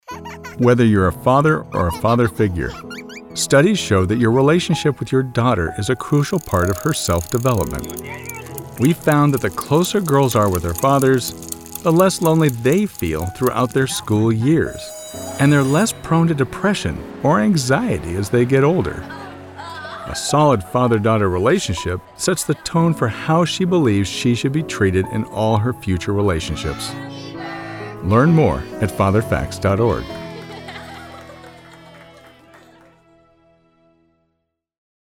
Commercial Demo
A voice actor with a smooth, trustworthy, and personable tone.
Heartfelt, sincere | PSA
• Microphone: Shure SM7B